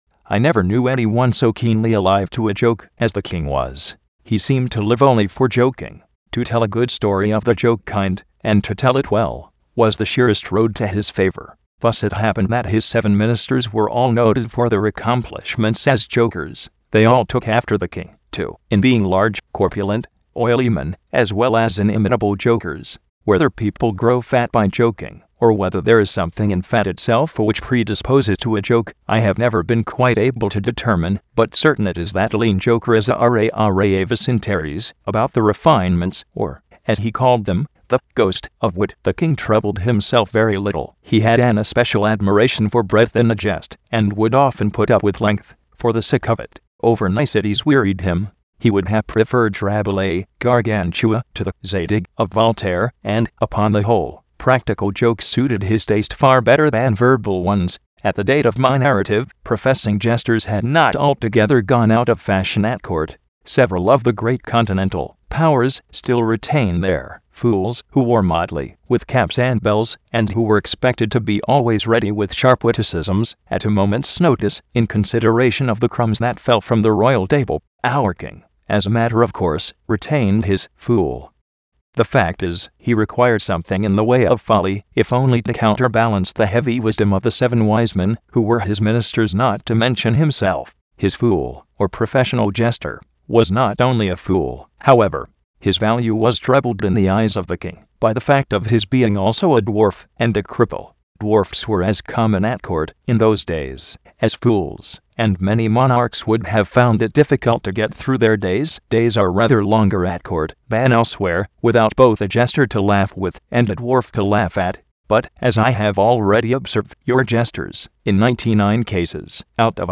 Здесь представлены образцы звучания Английских голосов.
(ATT Mike (En US)) Edgar Allan Poe - Hop-Frog
ATT Mike.mp3